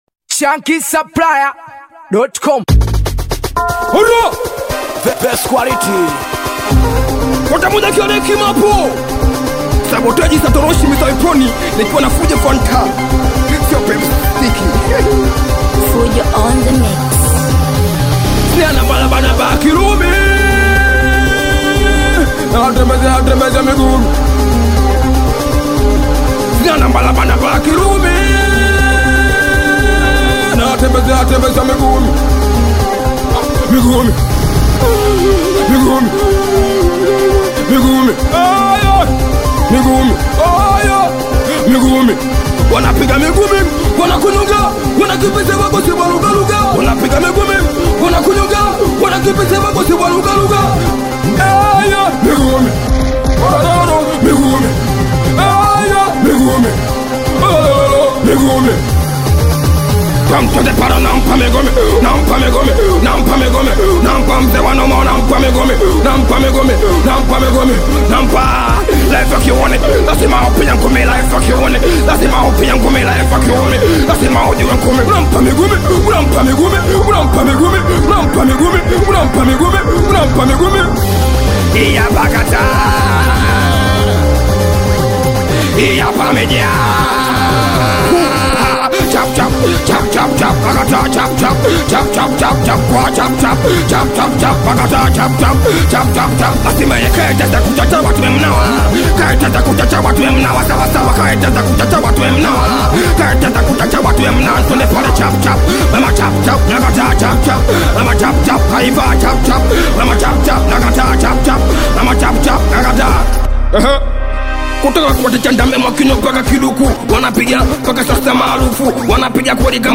SINGELI